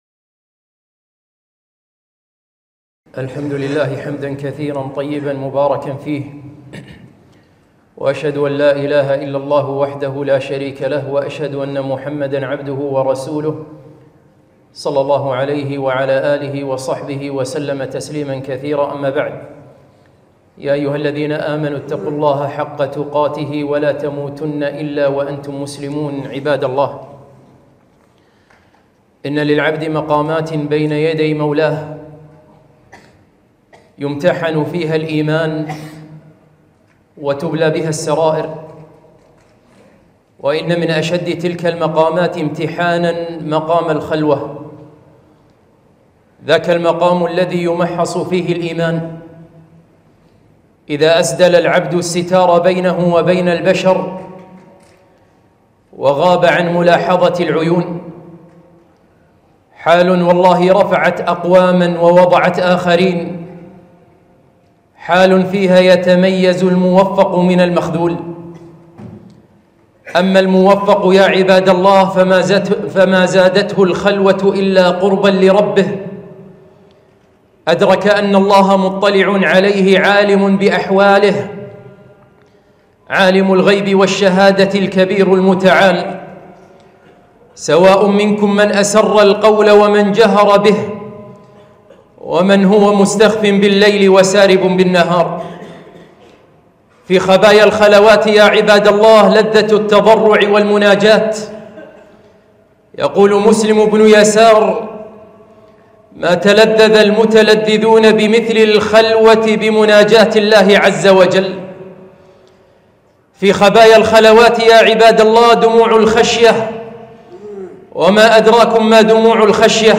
خطبة - خبايا الخلوات